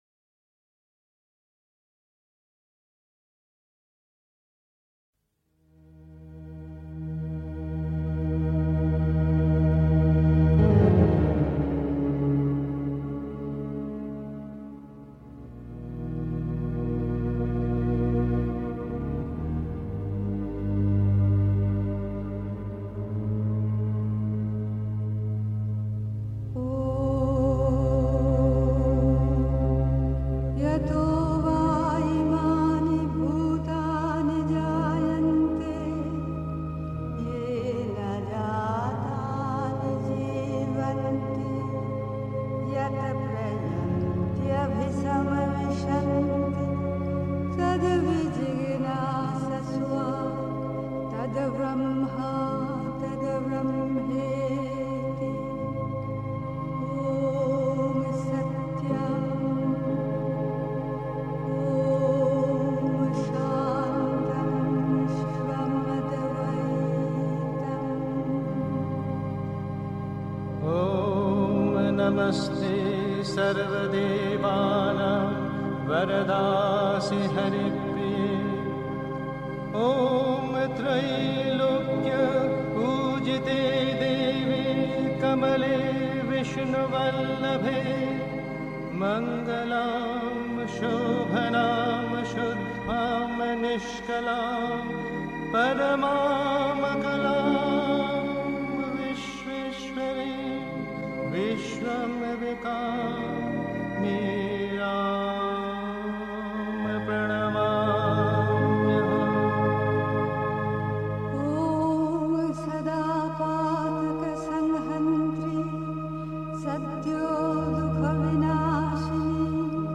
1. Einstimmung mit Sunils Musik aus dem Sri Aurobindo Ashram, Pondicherry. 2. Es gibt zwei Yogawege (Die Mutter, CWM Vol 3, pp. 4-5) 3. Zwölf Minuten Stille.